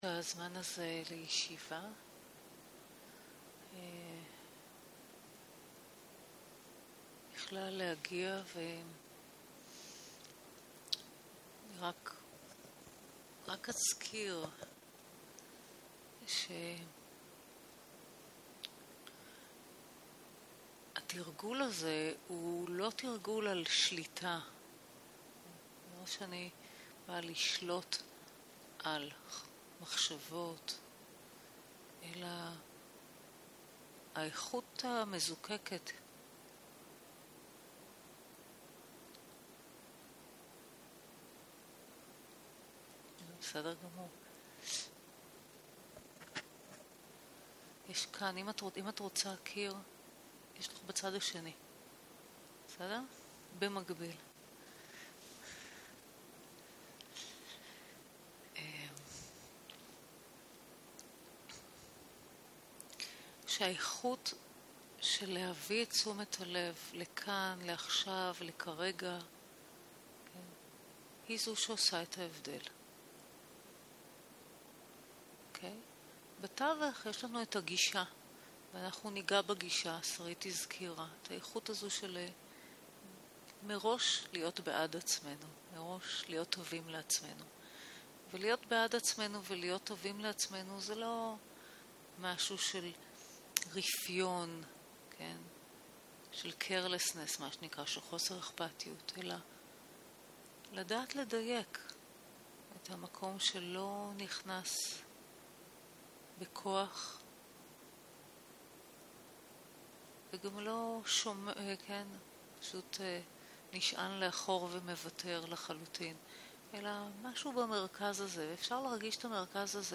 יום 1 - ערב - מדיטציה מונחית - מדיטציה להגעה הנה - הקלטה 1 0:00 0:00 סוג ההקלטה: Dharma type: Guided meditation שפת ההקלטה: Dharma talk language: Hebrew